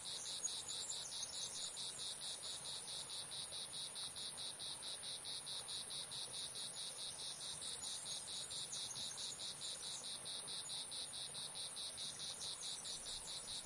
蟋蟀2
在前景鸟歌曲和昆虫嗡嗡声的蟋蟀在背景中。
Tag: 鸟儿 河流 蟋蟀 氛围 昆虫 性质 现场记录